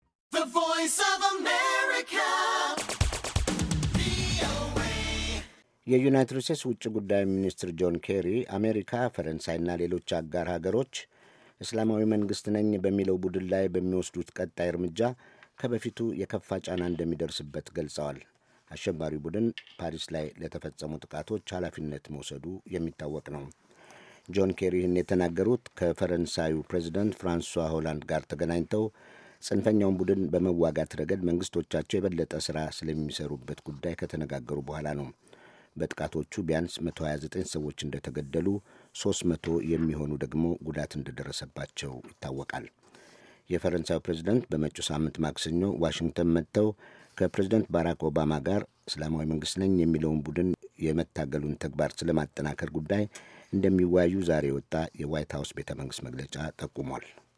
የፈረንሣዩ ፕረዚደንት ፍራንስዋ ኦላንድ በመጪው ማክሰኞ ዋሽንግተን መጥተው ከፕሬዝደንት ባራክ ኦባማ ጋር እስላማዊ መንግስት ነኝ የሚለውን ቡድን የመታገሉን ተግባር ስለማጠናከር ጉዳይ እንደሚወያዩ የዋት ሃውስ ቤተ መንግሥት መግለጫ ጠቁሟል። ዘገባውን ከተያያዘው የድምጽ ፋይል በመጫን ያዳምጡ።